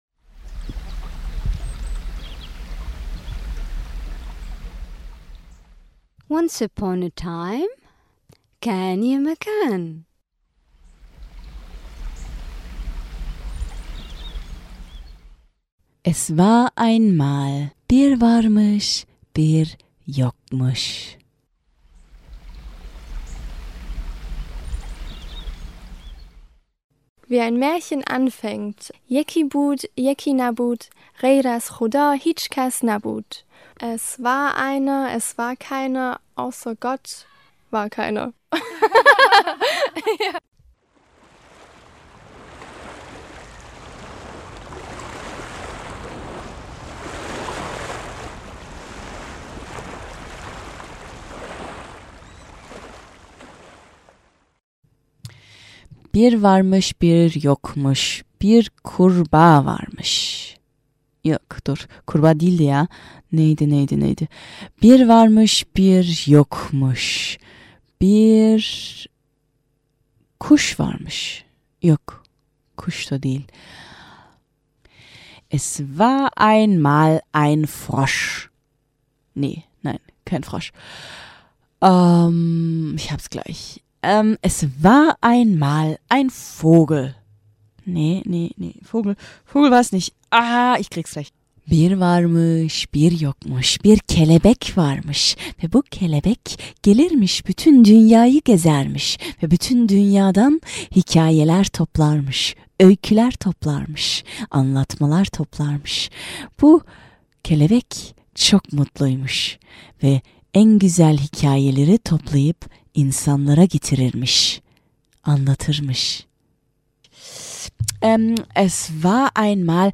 Und als kreative Theaterfrau hat sie daraus gleich eine Art Rap gemacht - ein wunderbares Beispiel für ein Sprachspiel, das mit seinem Klang selbst eine der Sprache vollkommen Unkundige zu erfreuen vermag.
bir_varmis_bir_yokmus_rap.mp3